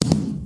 firework4.mp3